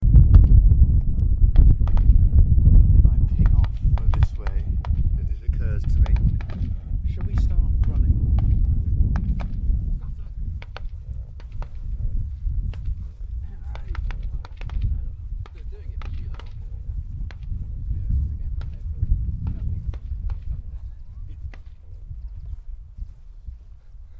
police firing range